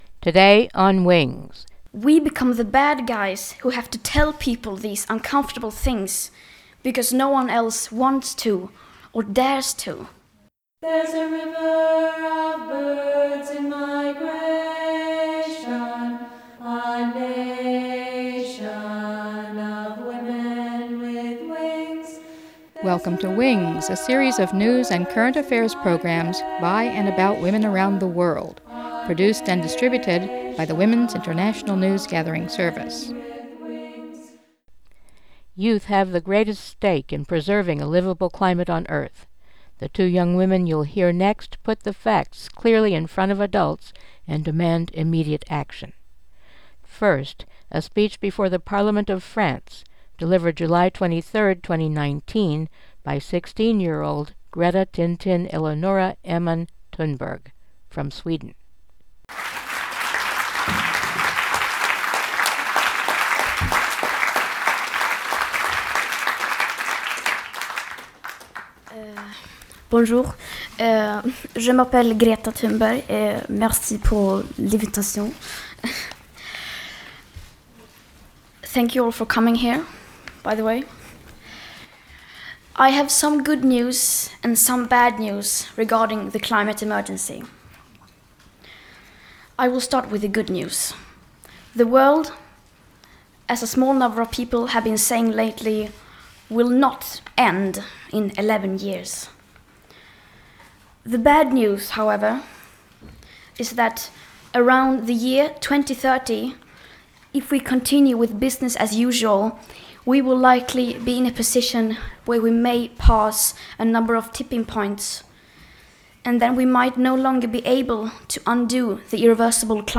Greta Thunberg and Kelsey Juliana speak frankly to adults
GretaThunberg at French Parliament